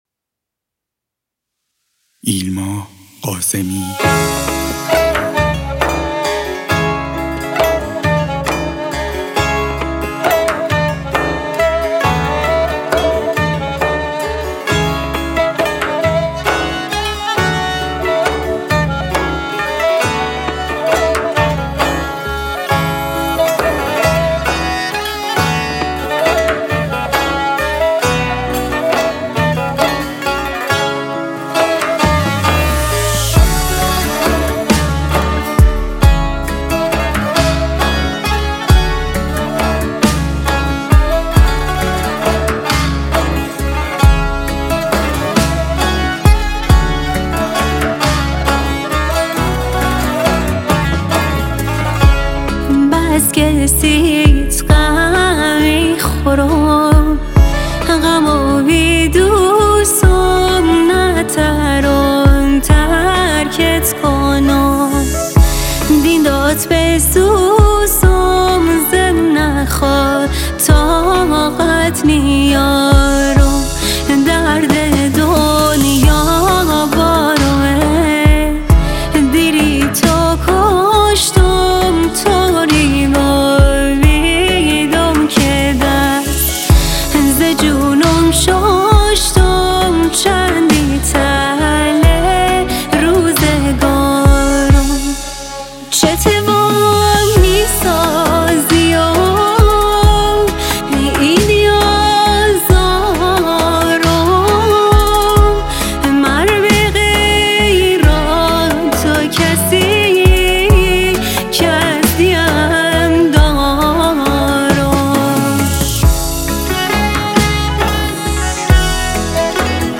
عالیه‌این‌اهنگ‌‌‌غمگین‌بودیم‌غمگین‌ترشدیم